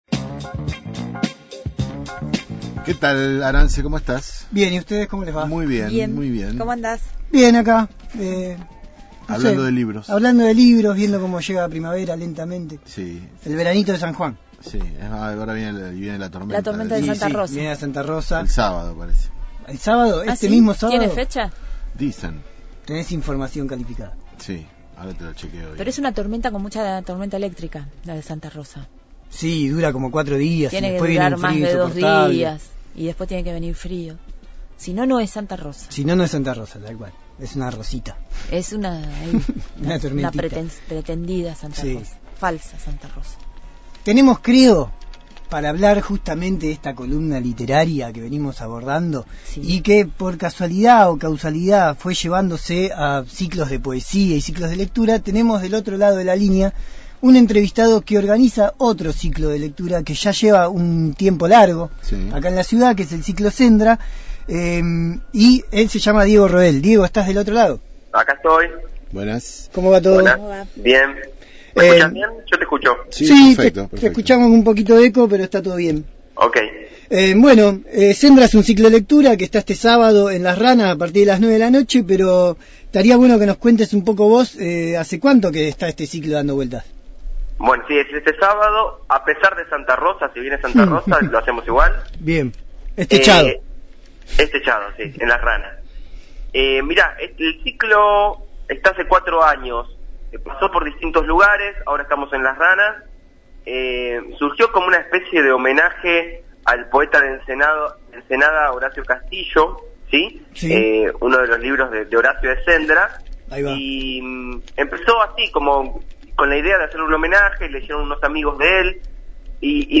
Conducción